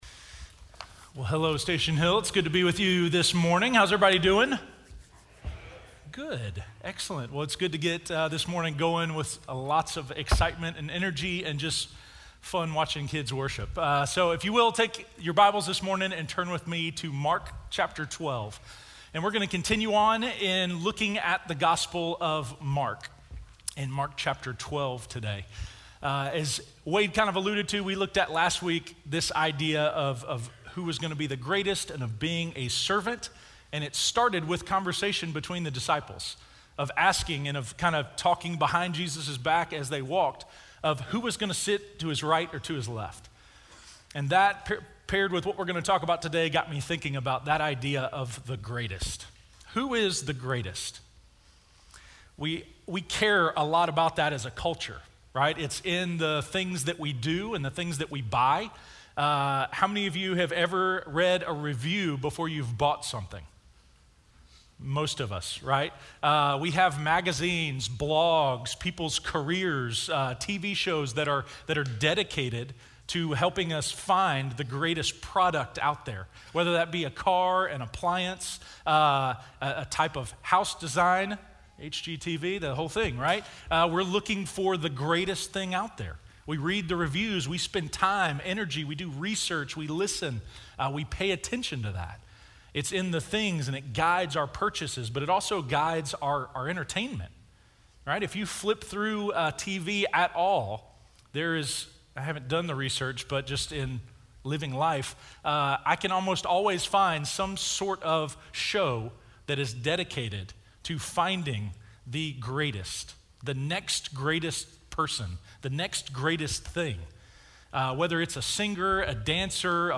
The Greatest Commandment - Sermon - Station Hill